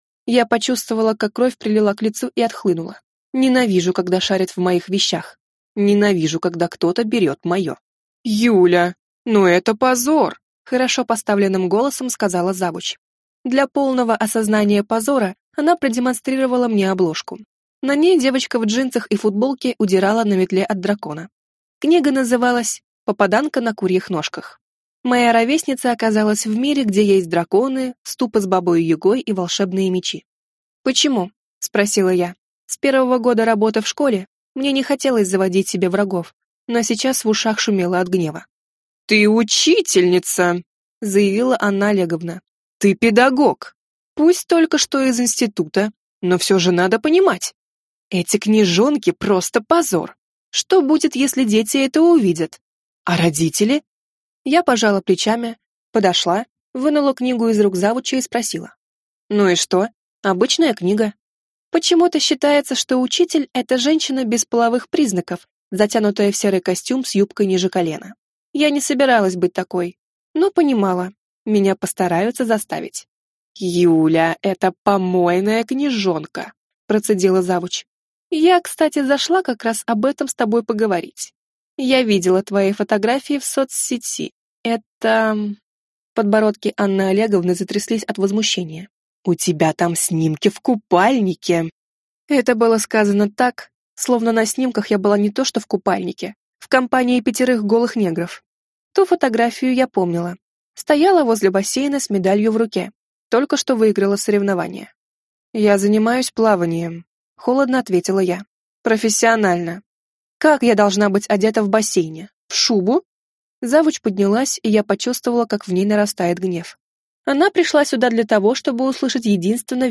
Аудиокнига Первоклассная учительница, дракон и его сын | Библиотека аудиокниг